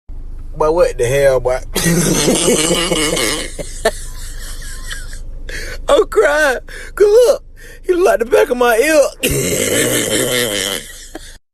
Boy What The Hell Boy Laugh